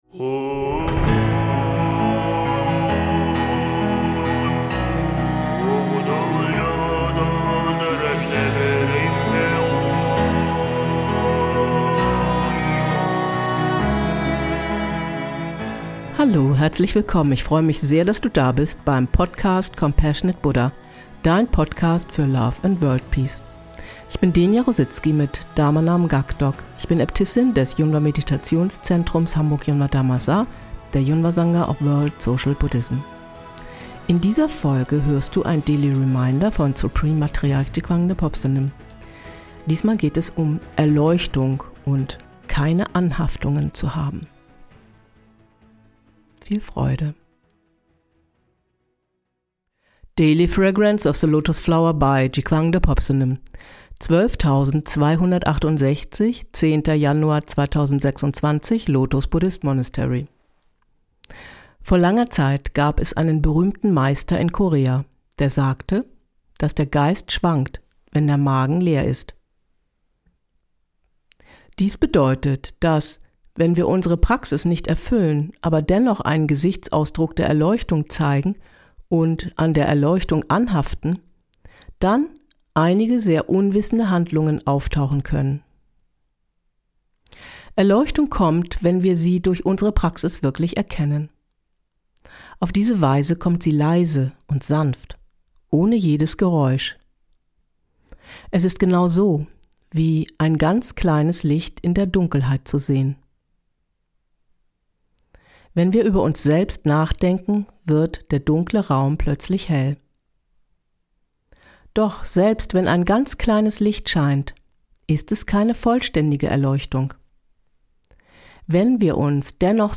Ganz leise und sanft